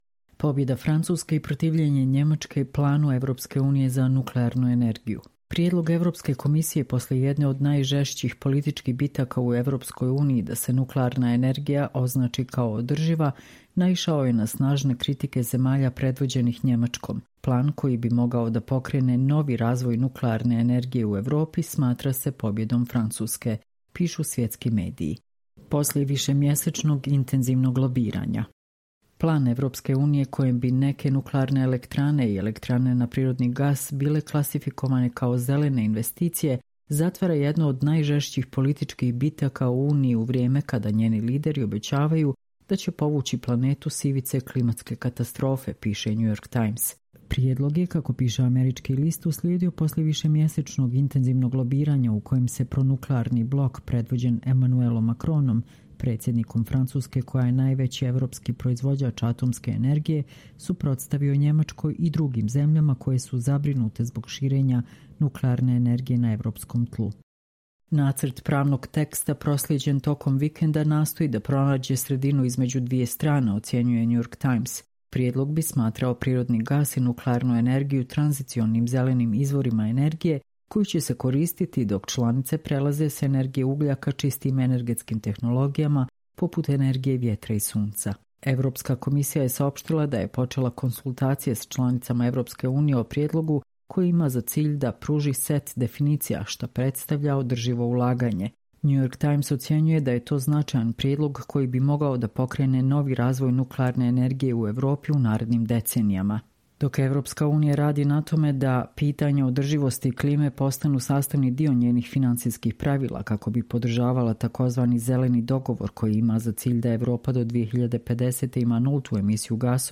Čitamo vam: Pobeda Francuske i protivljenje Nemačke planu EU za nuklearnu energiju